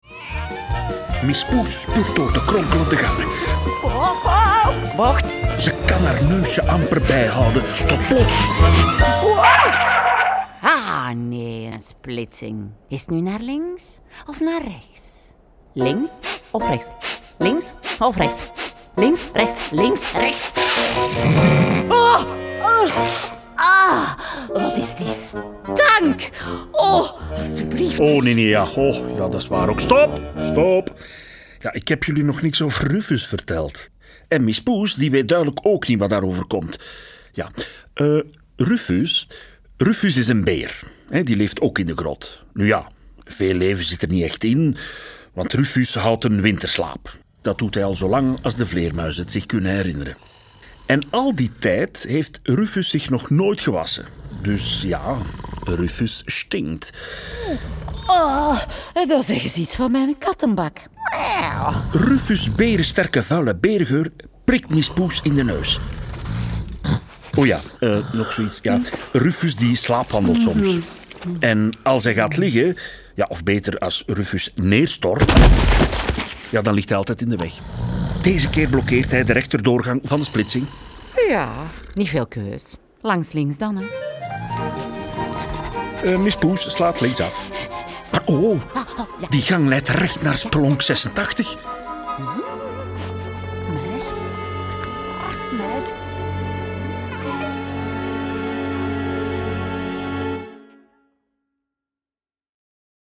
De rollen worden ingesproken door de béste acteurs en in bijhorend prentenboek staat ook een voorleestekst.
Heerlijk hoorspel